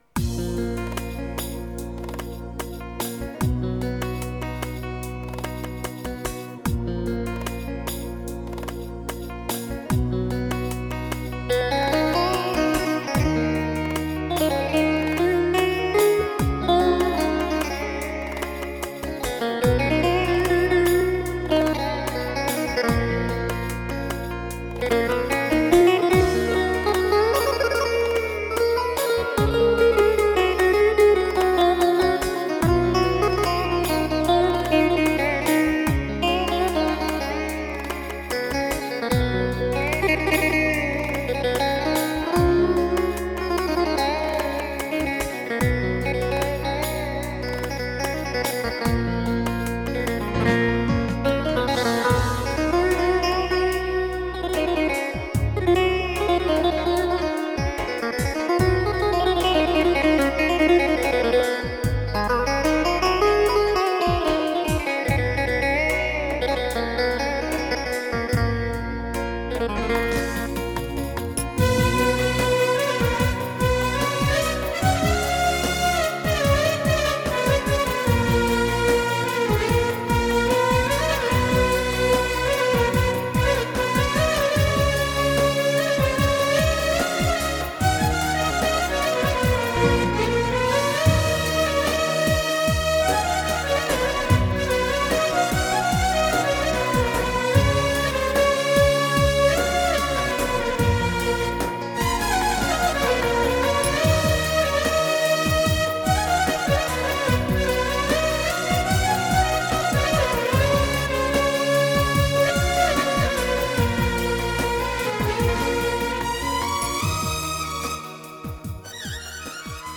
pa 500 yeni editlediğim ritim bağlama ve yaylı